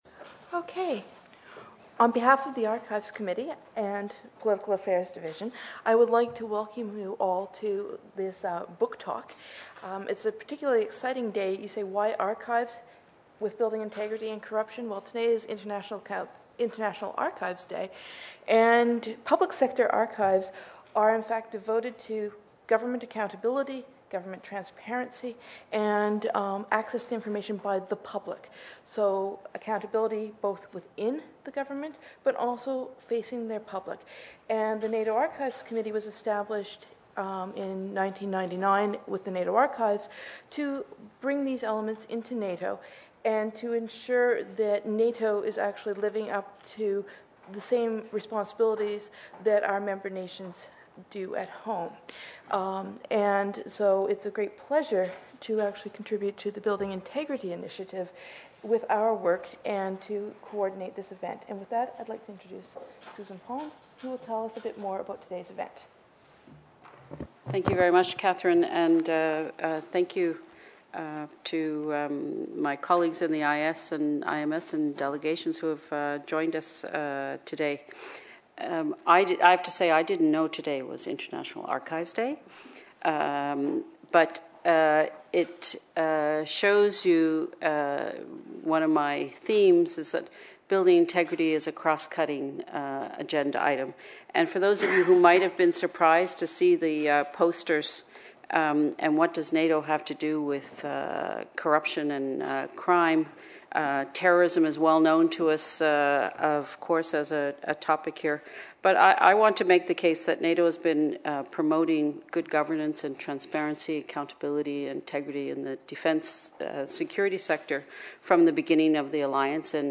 Book presentation at NATO HQ
Book Talk